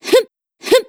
It is more like recording of my computer’s system audio, and it contains some repeating identical parts of same sound effect, so, i think there will be no problems at identifying same parts, as well, as they are actually completely same.
Well, it is hard to explain, but here you go with these 2 different samples of same gasps from some untitled game, that appear across all the recording.
Yeah, i already see they are SLIGHTLY different from each other, as you said.